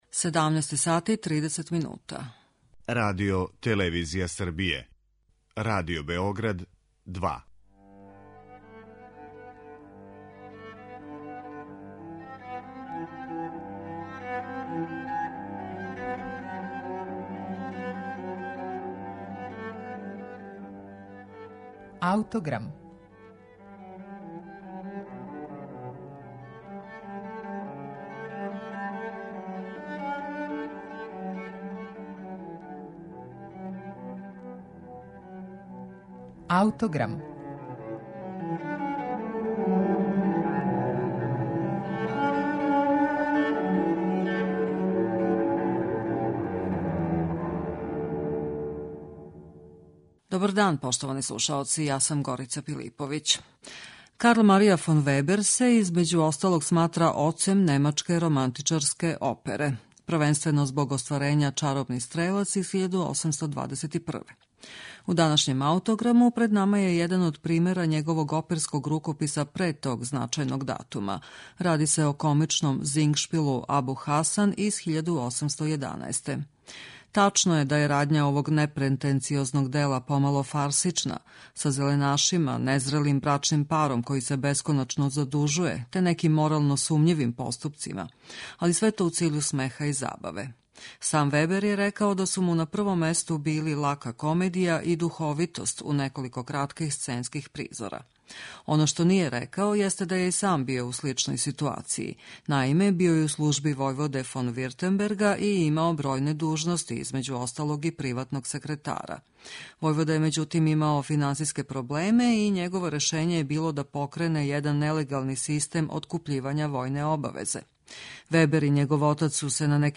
ЛАКА, ДУХОВИТА КОМЕДИЈА
Зингшпил, тј. опера са говорним деловима „Абу Хасан" премијерно је изведена 1811. у Минхену.